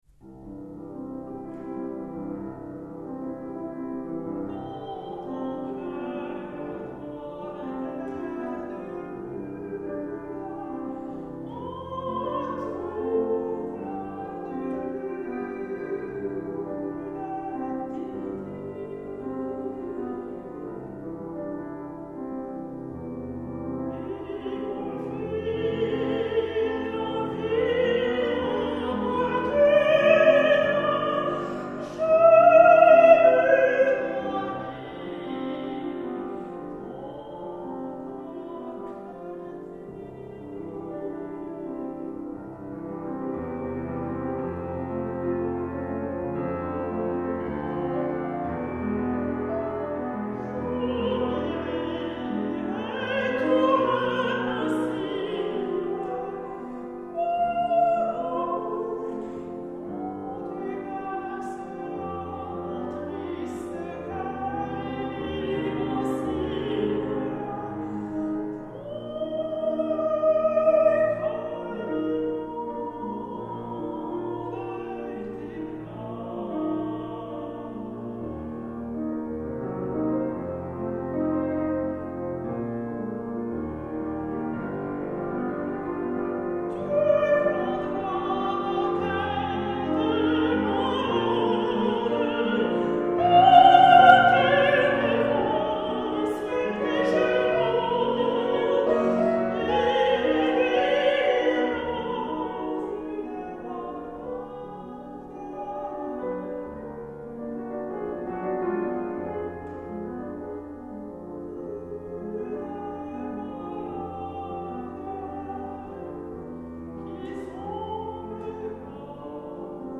мецо сопрано
пиано